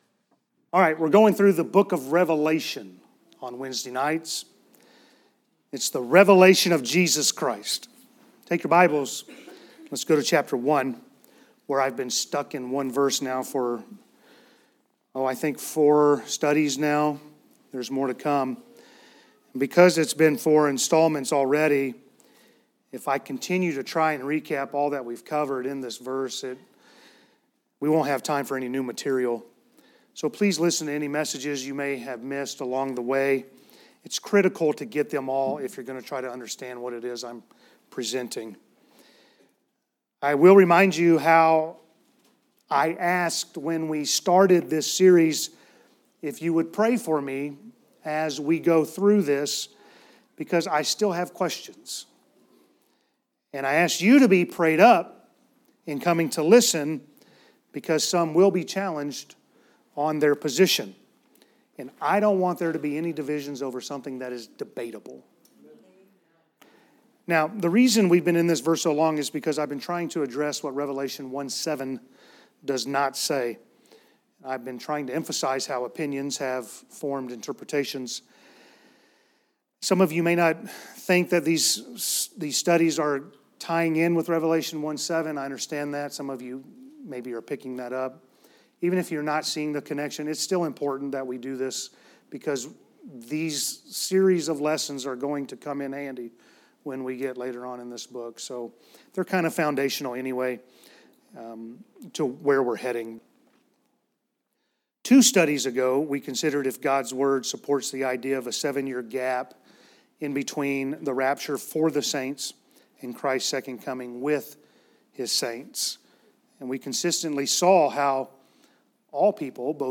Text: Revelation 1:7 A sermon from our Wednesday night series through the Revelation